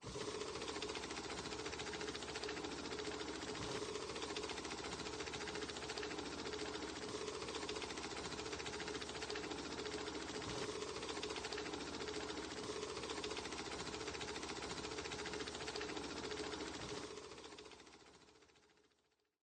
ball_raffle.mp3